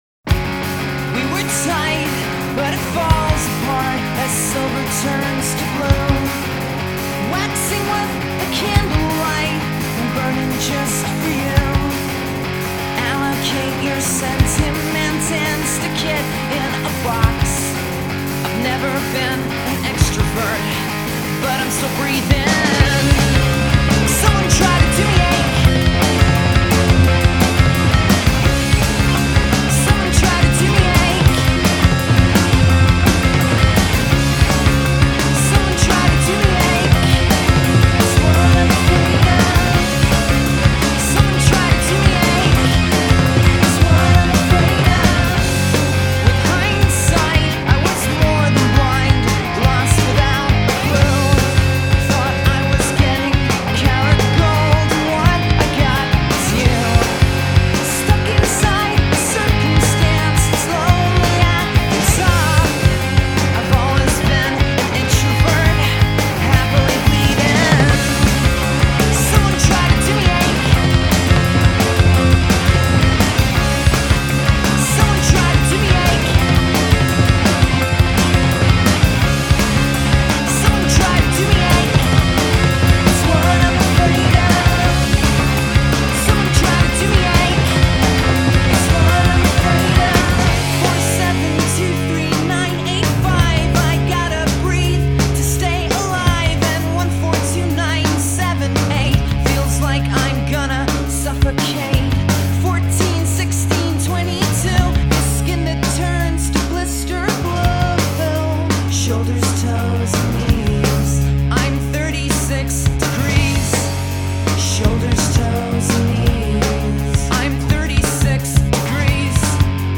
Alternative rock Pop punk